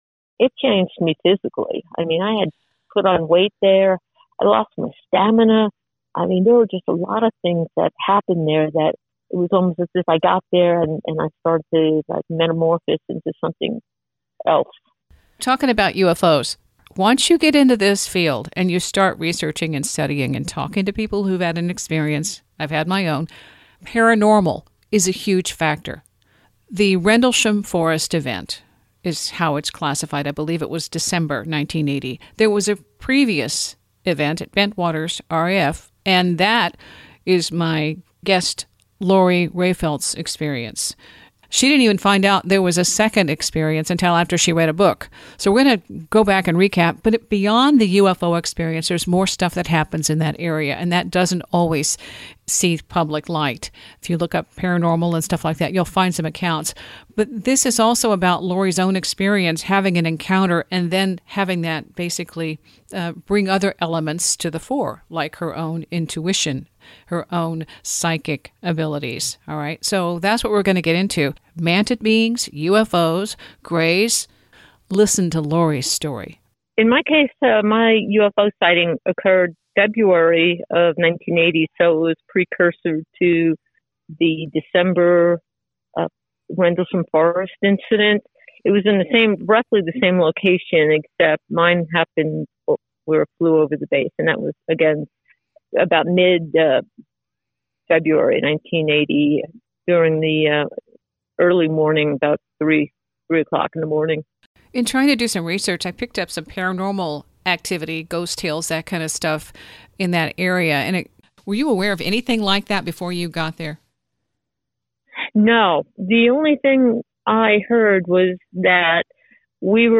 - Plato Archived 2021 interview.